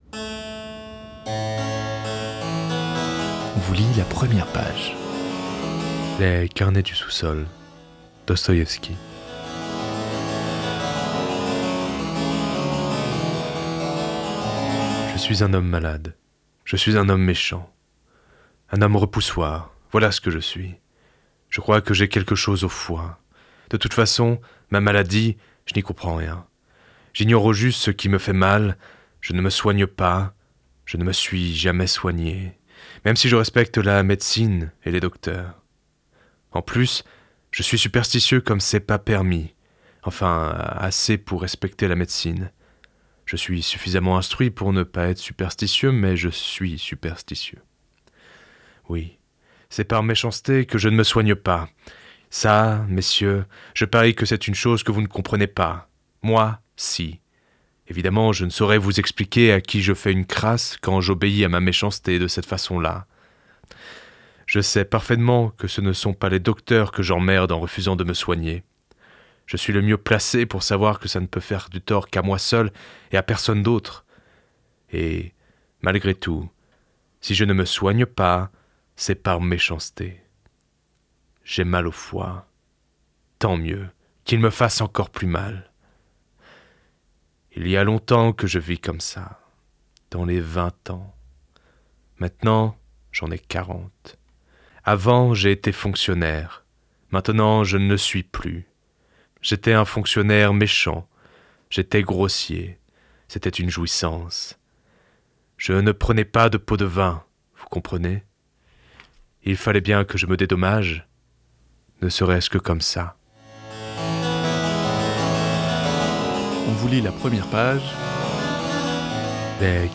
Dans l’émission Première page, RadioLà vous propose la lecture de l’incipit d’un roman.